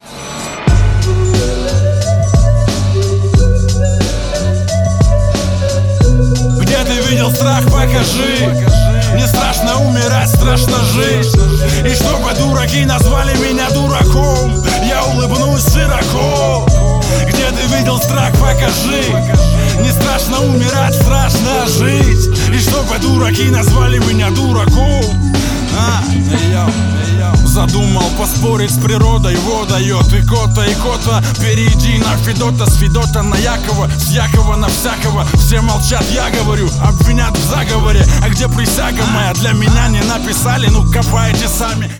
• Качество: 128, Stereo
громкие
русский рэп